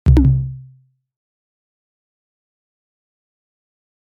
button 2.mp3